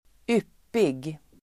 Uttal: [²'yp:ig]